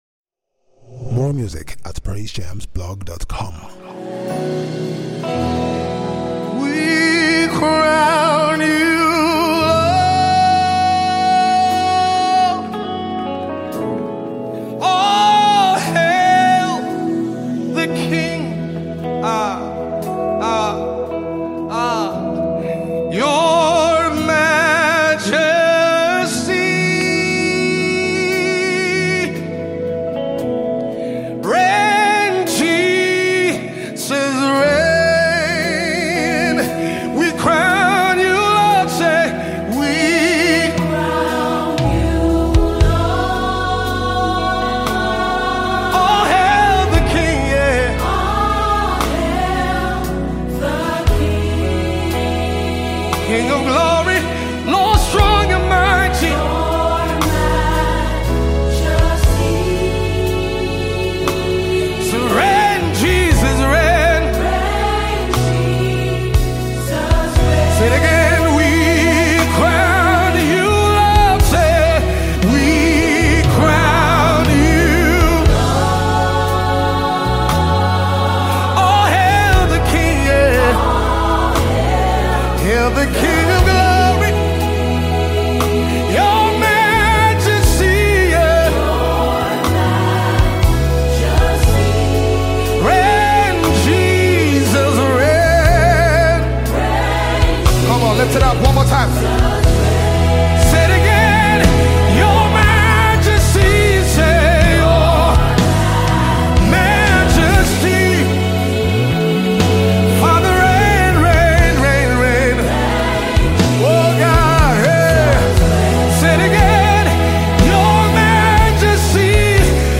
a majestic worship song